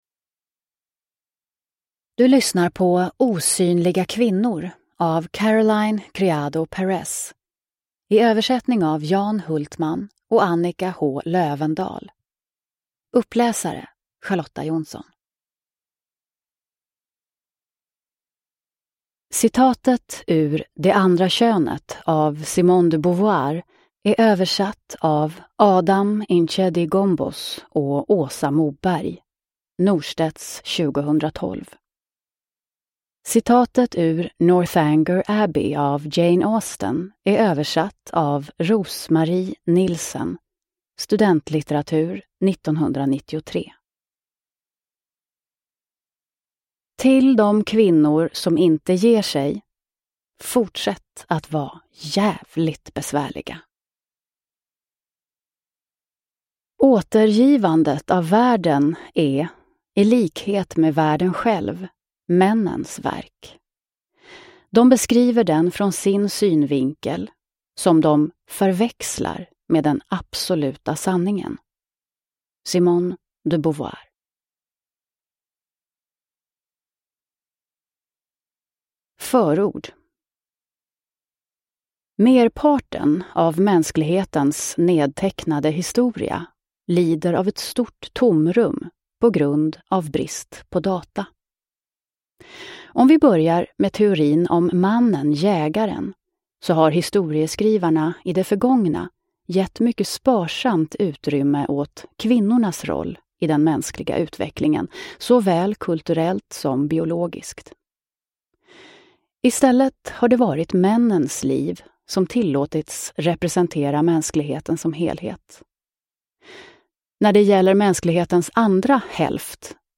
Osynliga kvinnor : hur brist på data bygger en värld för män – Ljudbok – Laddas ner